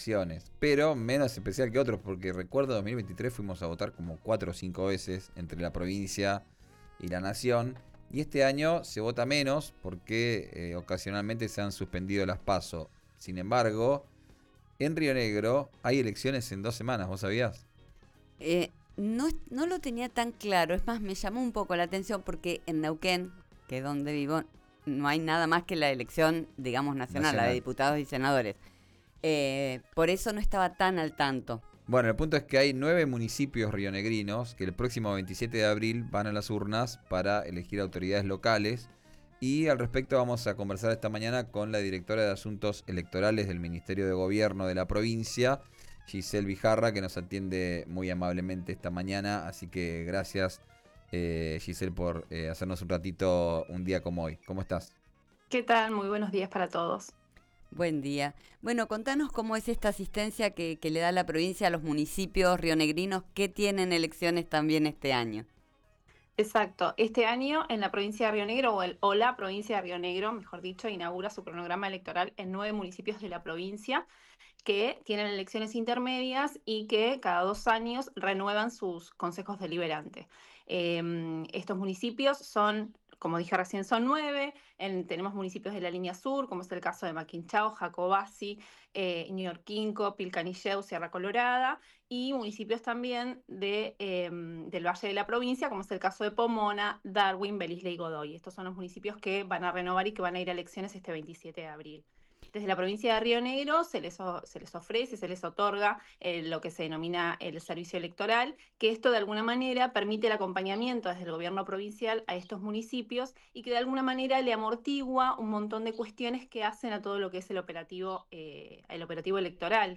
en el aire de RÍO NEGRO RADIO: